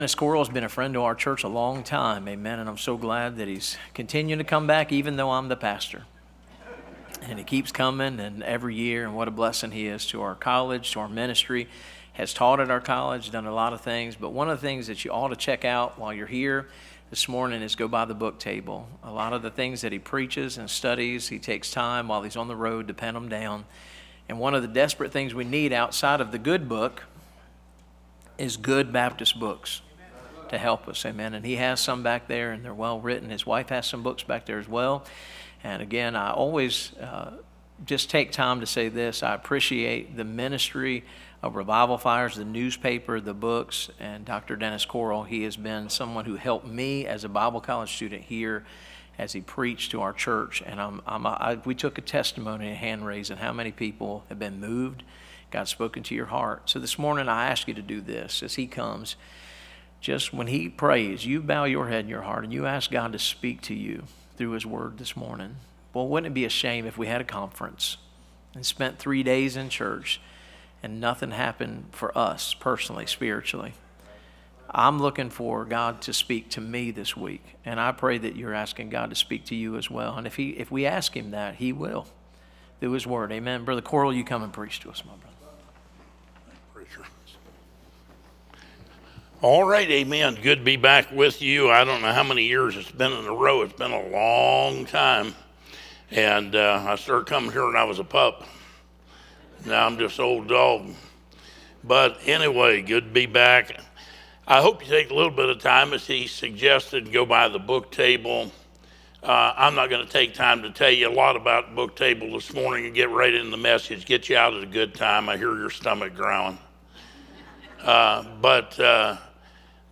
Series: 2025 Bible Conference